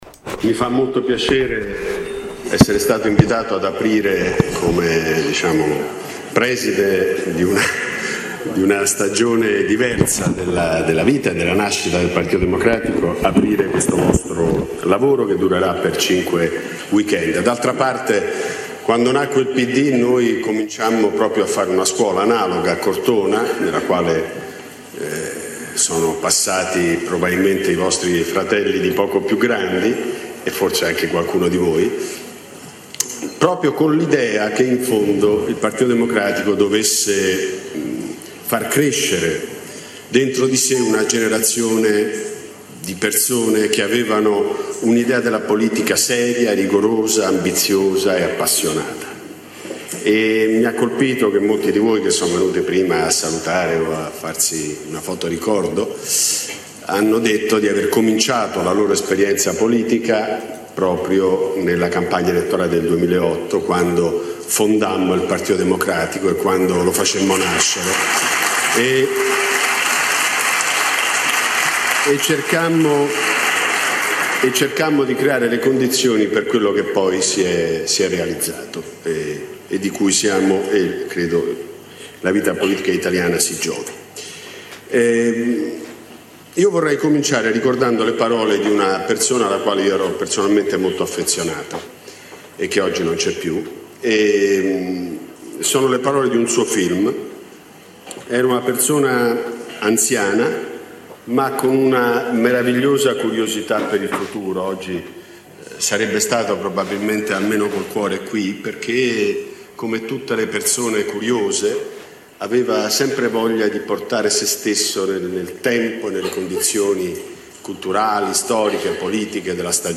WALTER VELTRONI, intervento a Classe democratica – Scuola di formazione del PD, 6 febbraio 2016